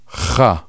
Se pronuncia j como en castellano
Pronunciación